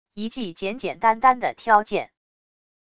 一记简简单单的挑剑wav音频